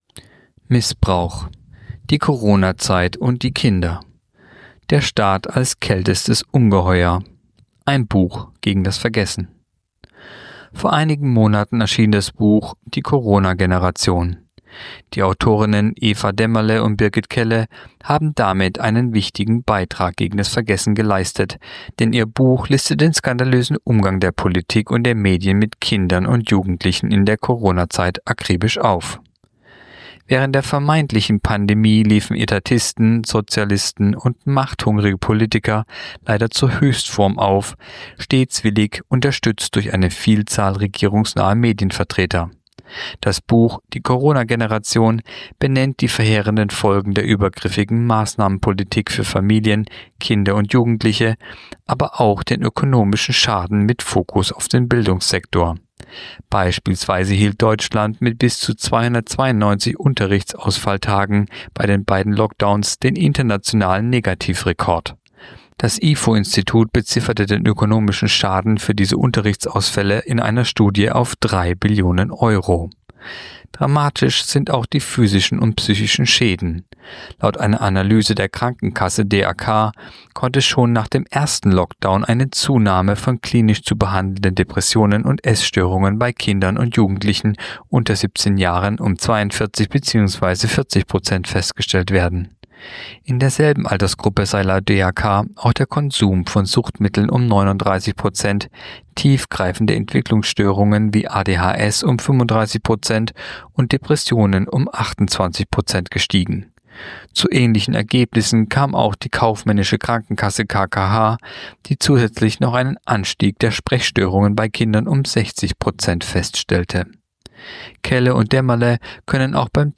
Kolumne der Woche (Radio)Die Corona-Zeit und die Kinder